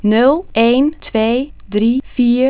Stel dat we de eerste 4096=2.^12 punten (het woord "nul" van het tijddomeinsignaal
Rond sample t = 0.040 s = 40 ms begint de /n/.
Dit is de overgang naar de /u/.
Dit plaatje geeft van de eerste 4000 samples (0.5 s) van x, gesampled op 8000 Hz , met behulp van een 128-dimensionale FFT (dus nfft/2+1 = 65 verschillende basisvectoren), elke 10 ms het Fourierspectrum op basis van een "Hanning window".